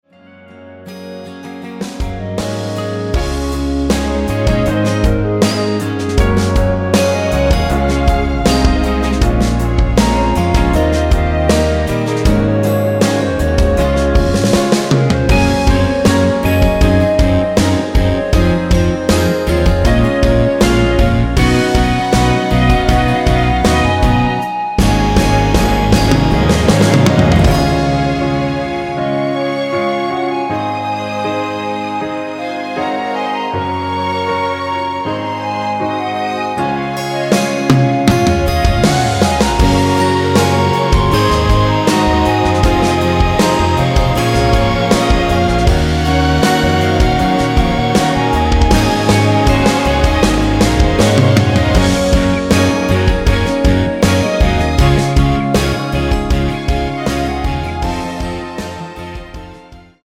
원키에서(+2)올린 멜로디 포함된 편집 MR입니다.(미리듣기 참조)
Db
앞부분30초, 뒷부분30초씩 편집해서 올려 드리고 있습니다.
(멜로디 MR)은 가이드 멜로디가 포함된 MR 입니다.